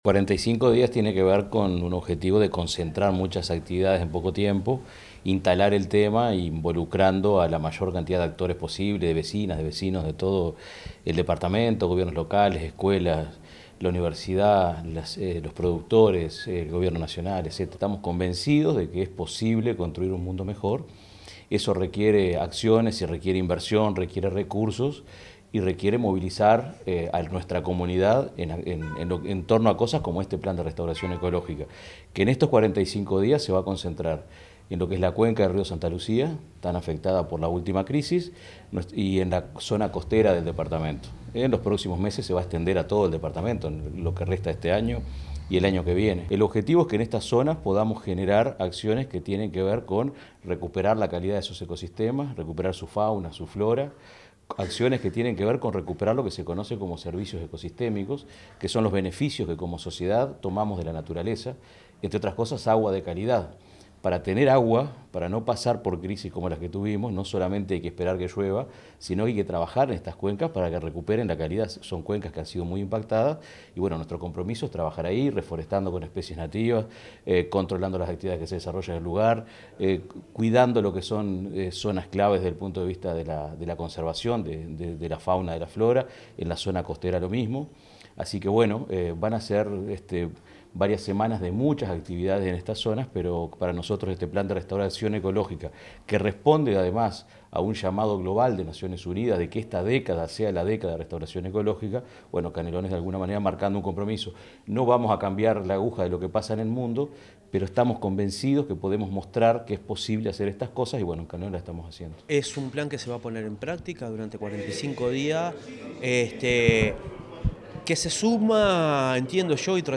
El Gobierno de Canelones, a través de la Dirección General de Gestión Ambiental, realizó la presentación del plan 45 días de restauración ecológica en Canelones, que se desarrolló en la Sala Beto Satragni del Complejo Cultural Politeama.